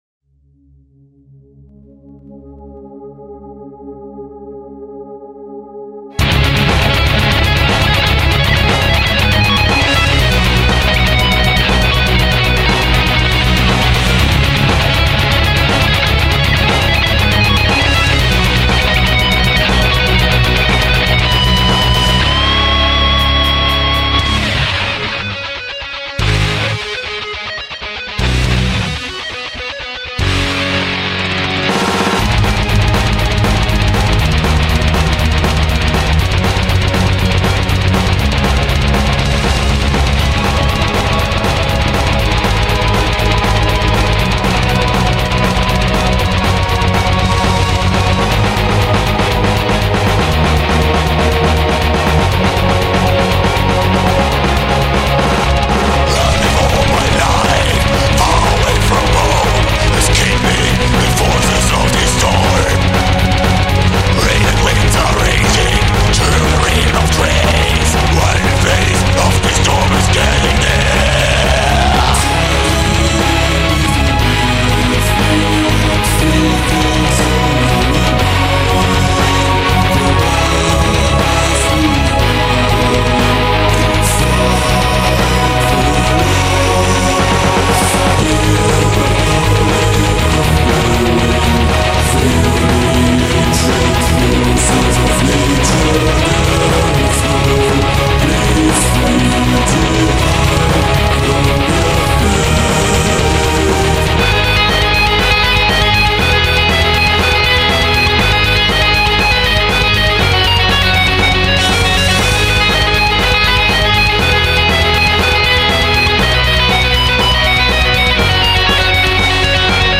Big thanks to him for providing us with this >:D This is a particularly kick-ass melodic death metal song.
Music / Rock
Really fast.